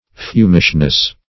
Fumishness \Fum"ish*ness\, n.